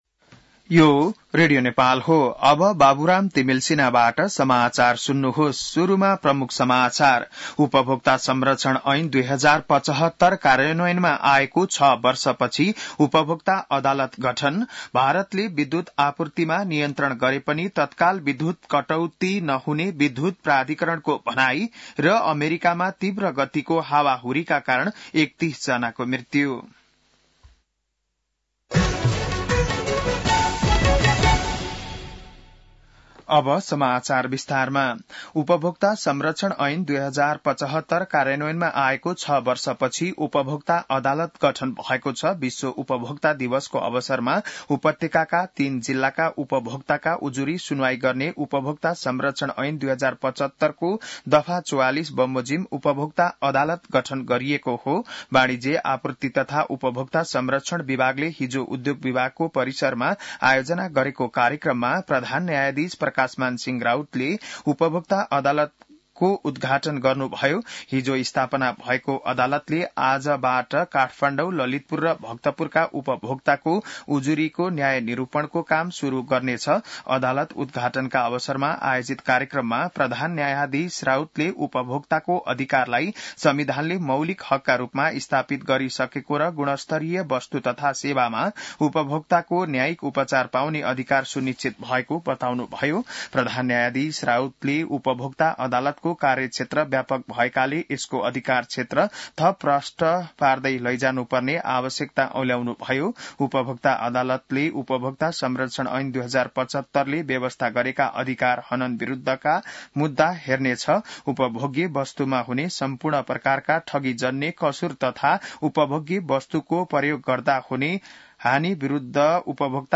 बिहान ९ बजेको नेपाली समाचार : ३ चैत , २०८१